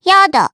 Pansirone-Vox-Deny_jp.wav